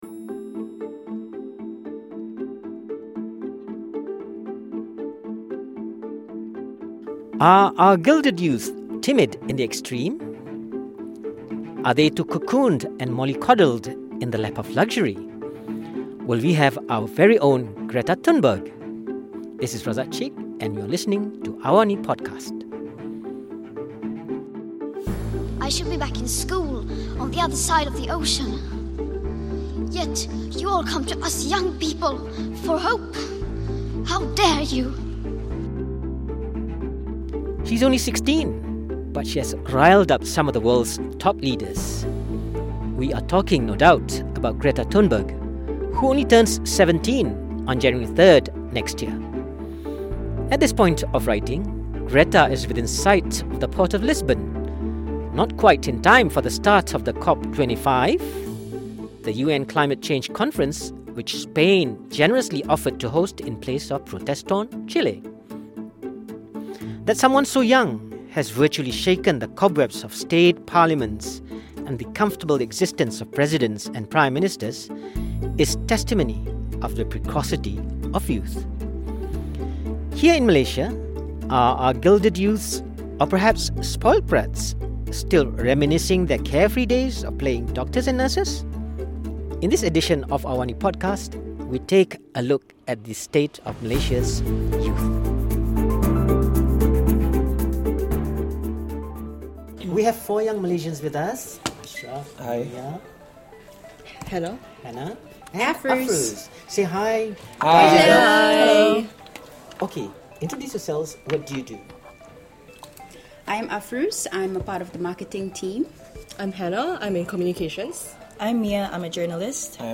discussing with the millennials on environmental awareness